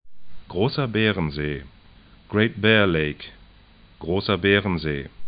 'gro:sɐ 'bɛ:rənze: